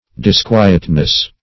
Search Result for " disquietness" : The Collaborative International Dictionary of English v.0.48: Disquietness \Dis*qui"et*ness\, n. Disturbance of quiet in body or mind; restlessness; uneasiness.